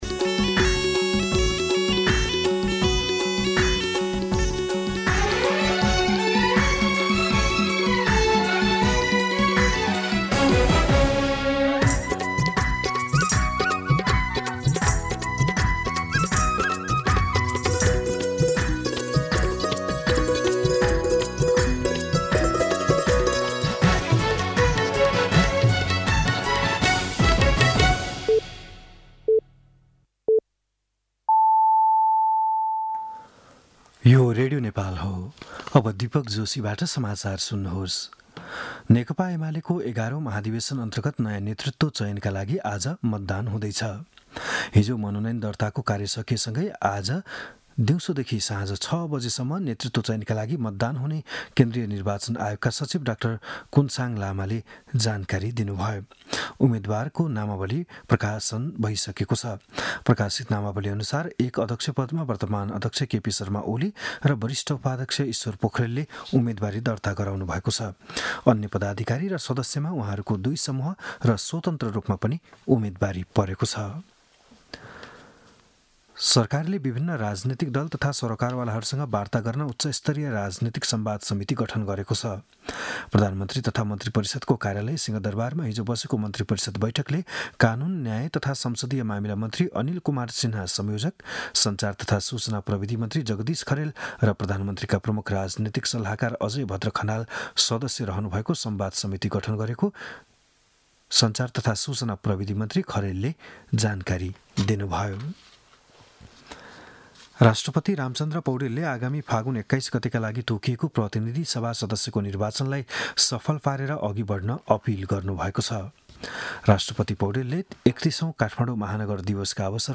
बिहान ११ बजेको नेपाली समाचार : १ पुष , २०८२